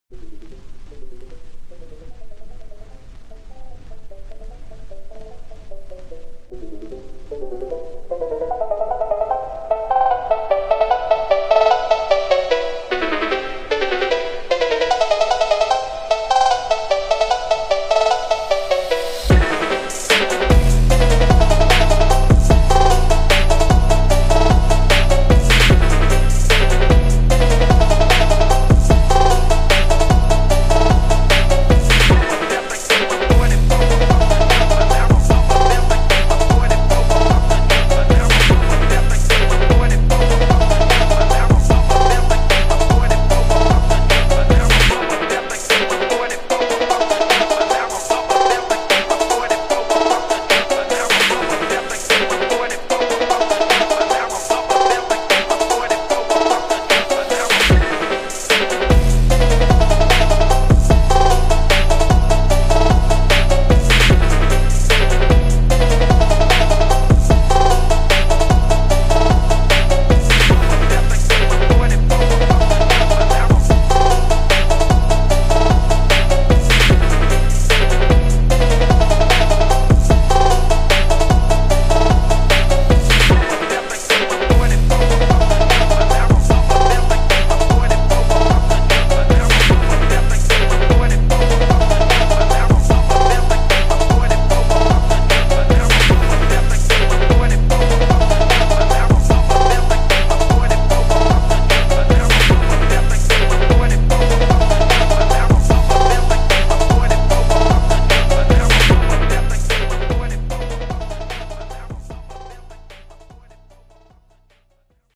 на пианино